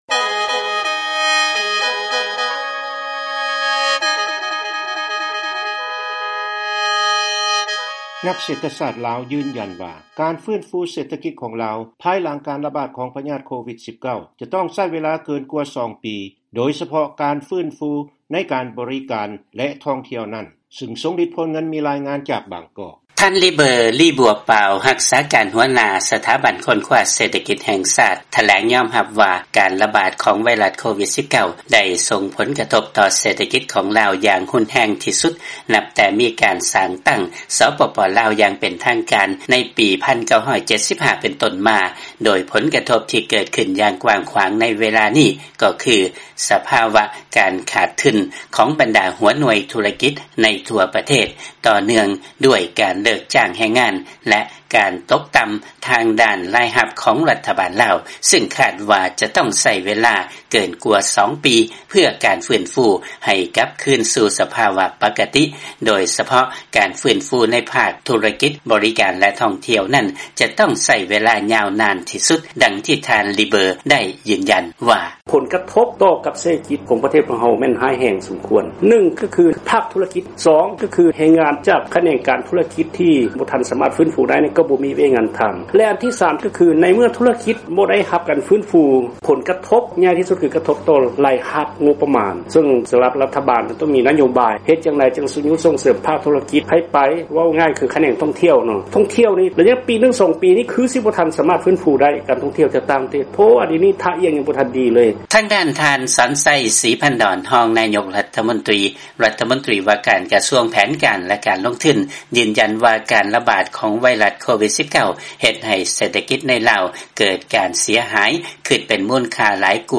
ຟັງລາຍງານ ນັກເສດຖະສາດ ລາວ ຢືນຢັນວ່າ ການຟື້ນຟູ ເສດຖະກິດ ພາຍຫຼັງການລະບາດ COVID-19 ຈະຕ້ອງໃຊ້ເວລາ ເກີນກວ່າ 2 ປີ